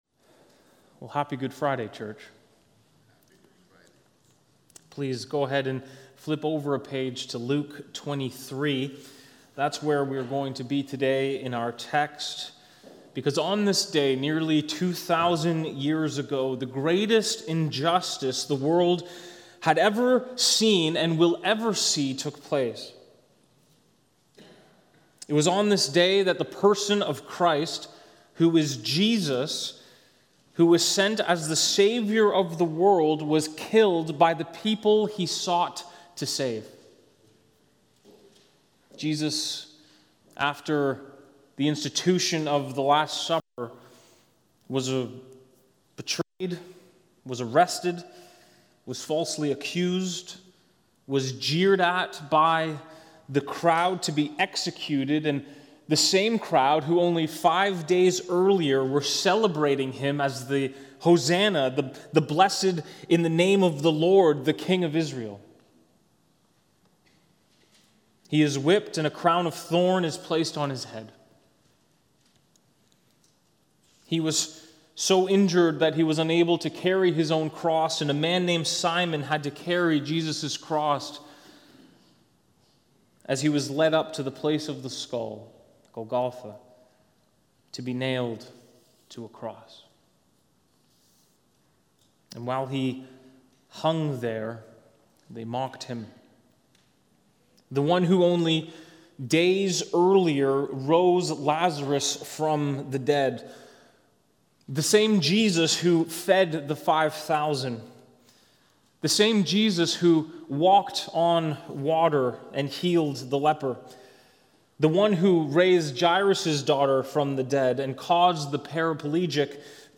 Good Friday